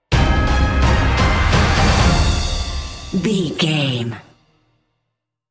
Epic / Action
Fast paced
Uplifting
Aeolian/Minor
Fast
brass
cello
double bass
drums
horns
orchestra
synthesizers